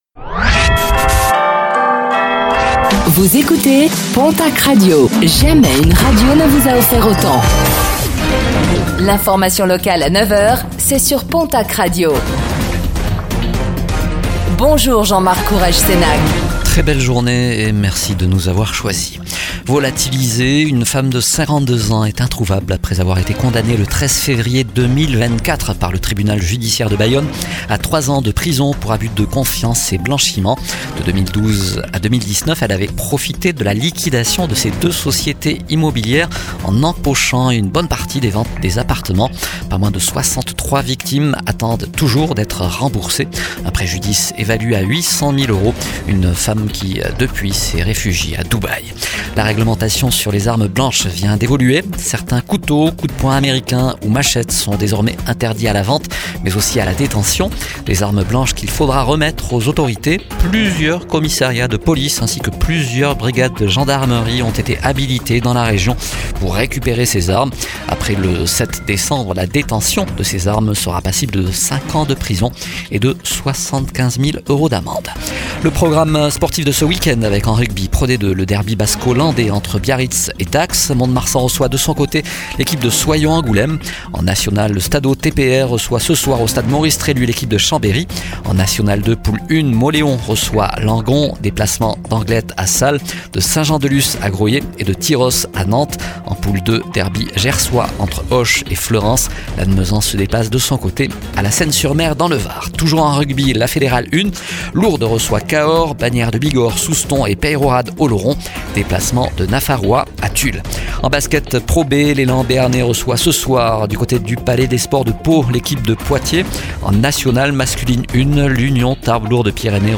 Réécoutez le flash d'information locale de ce vendredi 14 novembre 2025